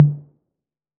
Tm8_HatxPerc37.wav